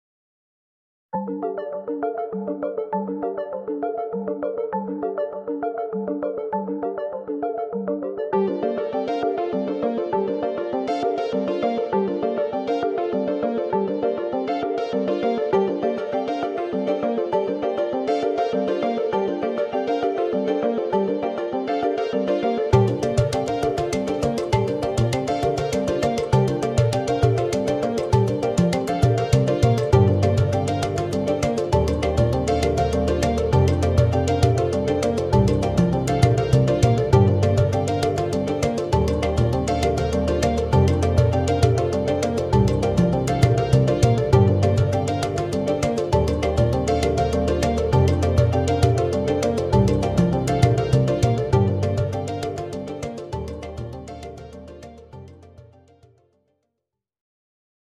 该库包括 4 类声音：弹拨弦、合成器实验、鼓舞人心的动作和打击乐循环。
打击乐循环类别包括用udu，皮肤鼓，tambuata和地板汤姆制成的循环。
主要灵感来自部落舞曲